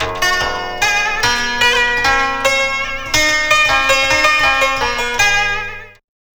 CHINAZITH3-L.wav